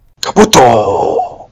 infinitefusion-e18/Audio/SE/Cries/KABUTO.mp3 at releases-April
KABUTO.mp3